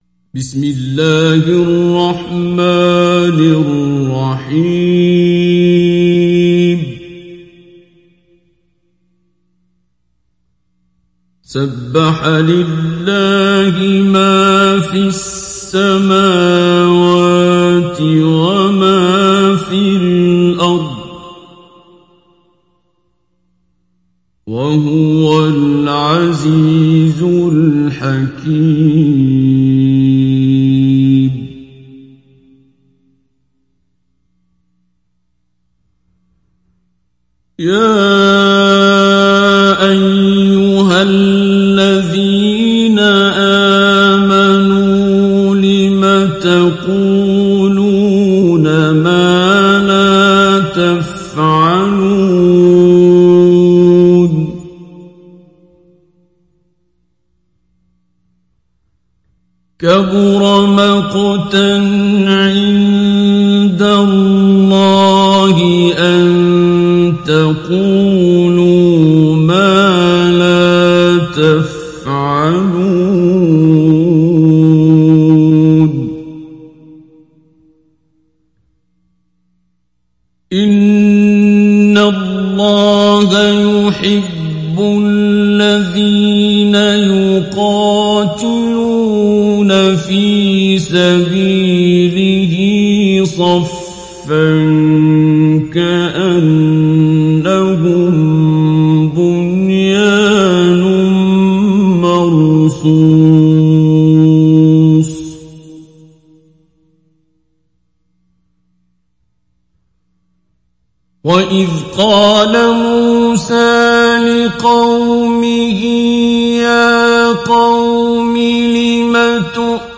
دانلود سوره الصف عبد الباسط عبد الصمد مجود